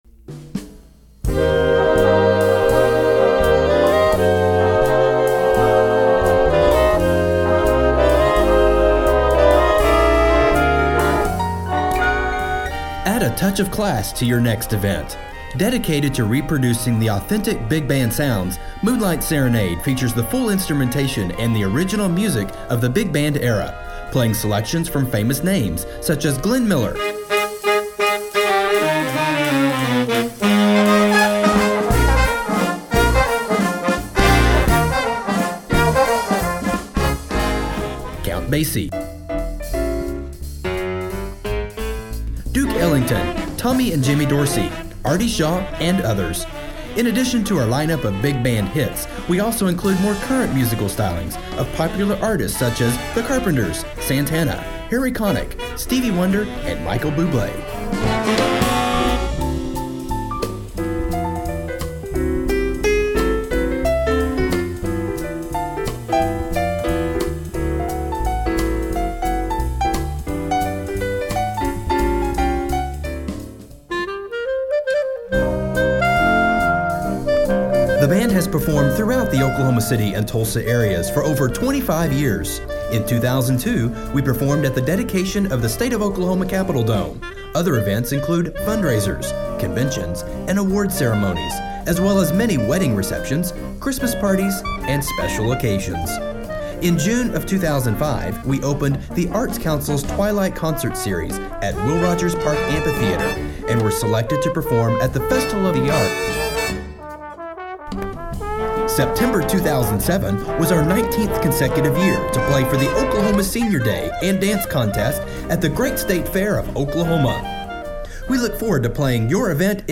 Jazz standards, big band music for Central US.